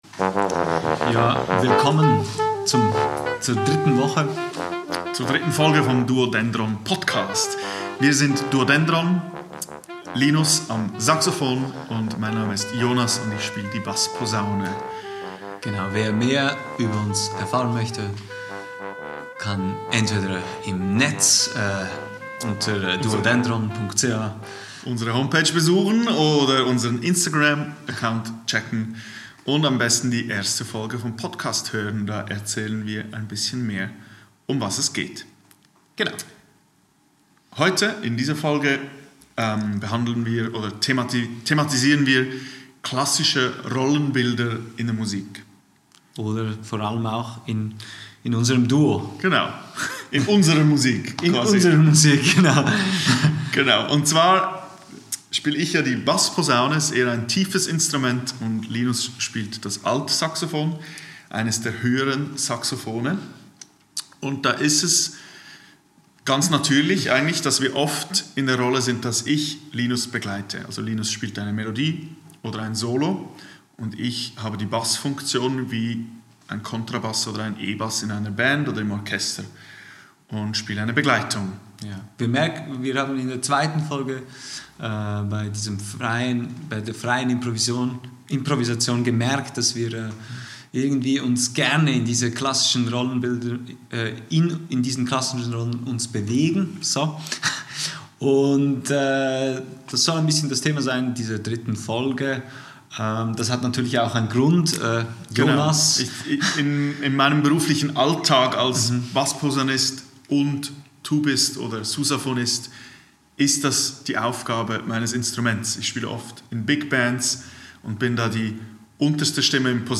Aufgenommen am 19.03.2024 im Foyer des Theater Burgbachkeller Zug Mehr